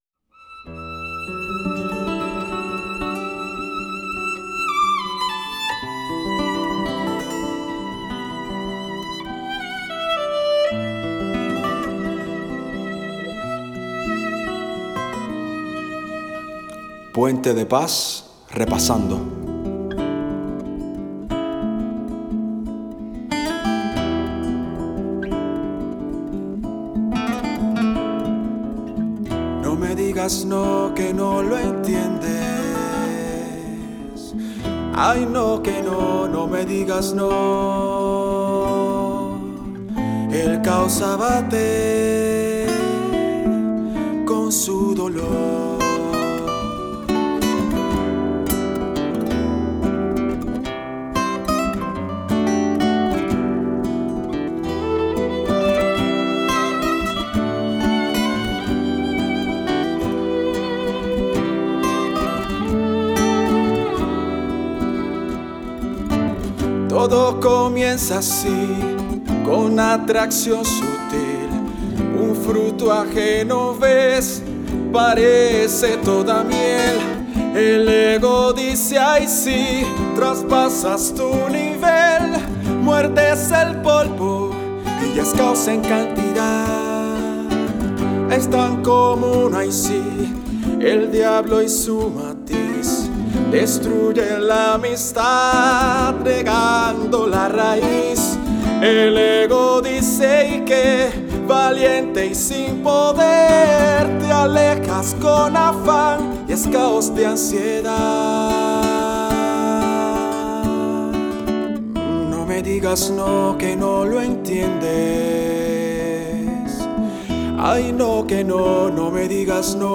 Mi canto a capela de una versión antigua de la canción “Caos nunca más” se puede escuchar aquí: